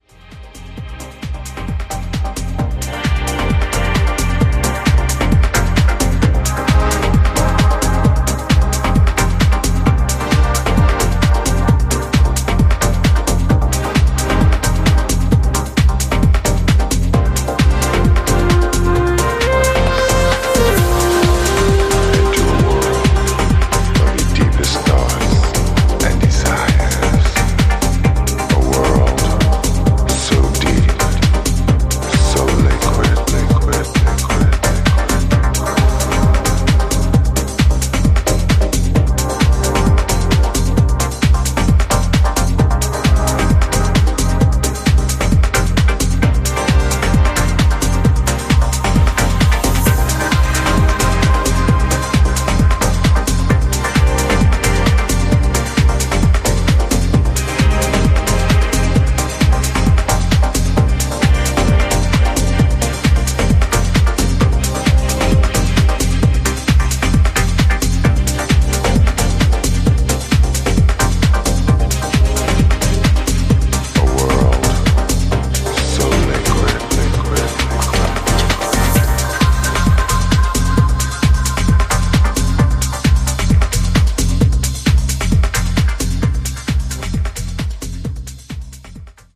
遊泳するシンセ・テクスチャーと感傷的なメロディー